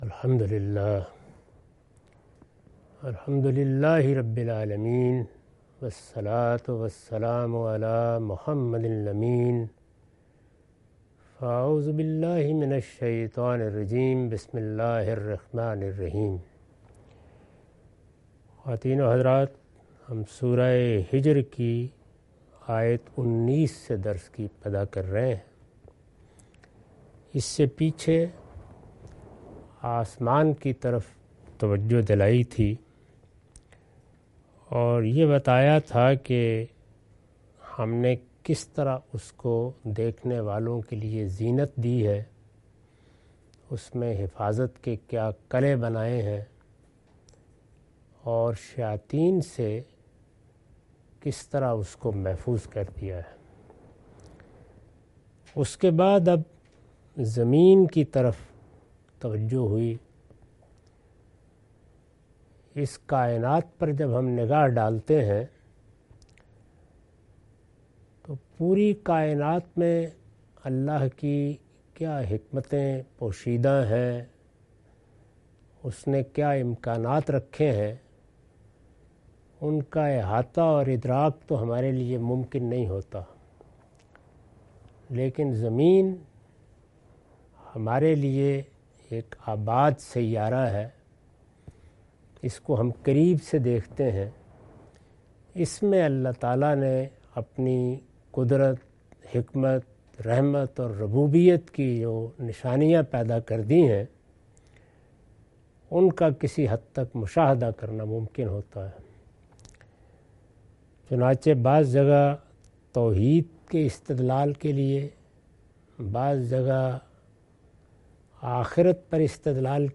Surah Al-Hijr- A lecture of Tafseer-ul-Quran – Al-Bayan by Javed Ahmad Ghamidi. Commentary and explanation of verses 19-27.